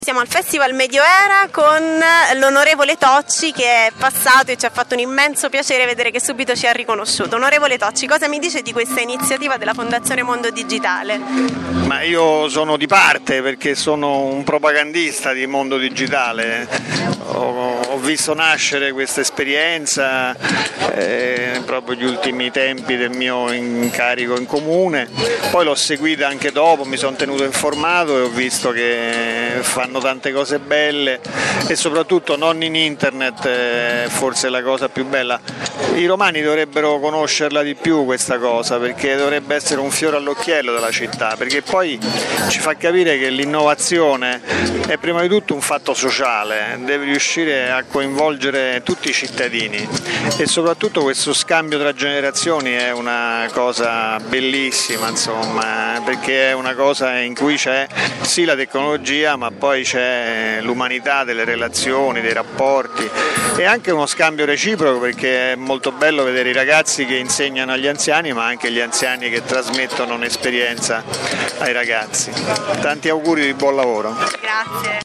Al dibattito sull’innovazione tecnologica ieri sera ha partecipato anche l’onorevole Walter Tocci, che da sempre segue le inizi...